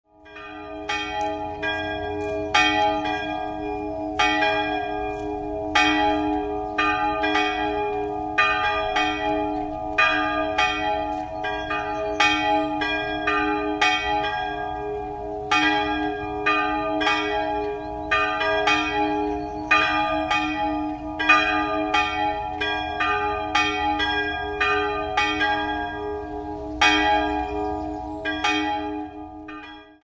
Beschreibung der Glocken
Die barocke Georgskirche aus dem 18. Jahrhundert mit einem älteren Turm im Nordosten des Ortes wurde 1993 umfassend renoviert und besitzt im Inneren drei sehr schöne Barockaltäre. 2-stimmiges Quart-Geläute: es''-as'' Eine Glockenbeschreibung folgt unten.